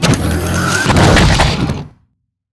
LiftDoorClose.wav